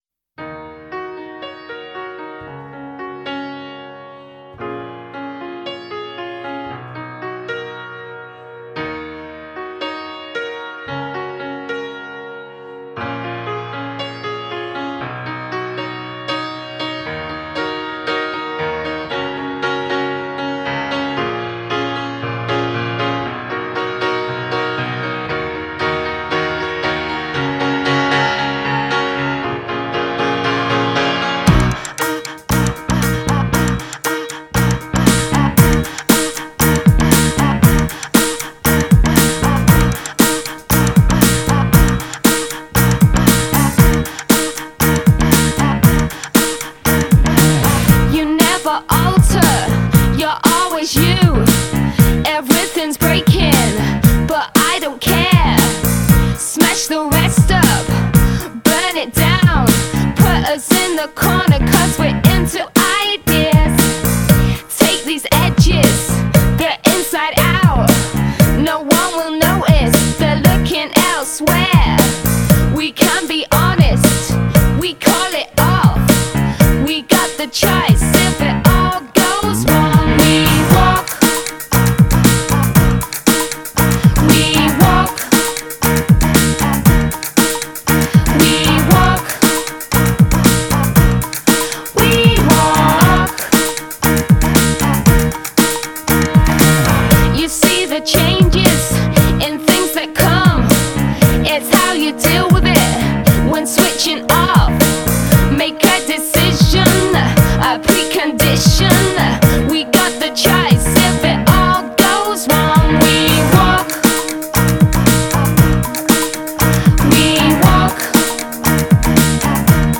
【另类摇滚】
前奏党的福音啊，声音由远至近，力度不断加大，最后带出女声部分，整首歌节奏感极强，让人不禁跟随鼓点晃动身体